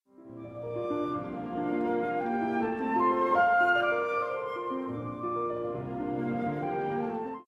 高校生の時に読んだ本、たぶん阿川弘之の論語知らずの論語読みかなにかではなかったかと思うが、あの誰でも知っている名曲、山田耕筰作「赤とんぼ」のメロディーが、シューマンの「序章と協奏的アレグロ Op.134」に何度も現れるという下りが出てくる。